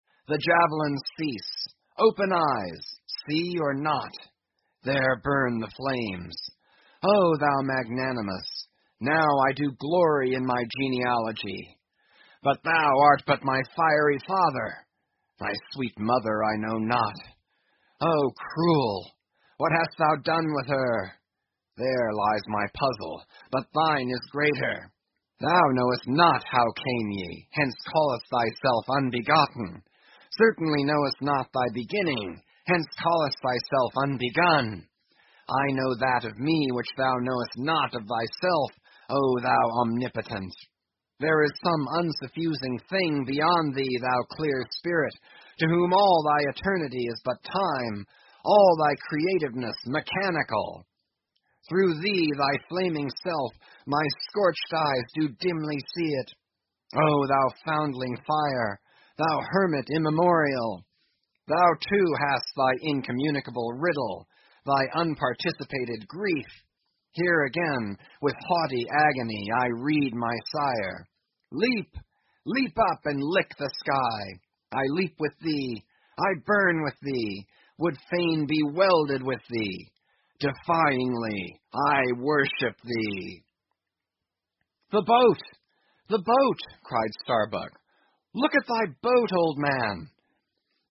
英语听书《白鲸记》第946期 听力文件下载—在线英语听力室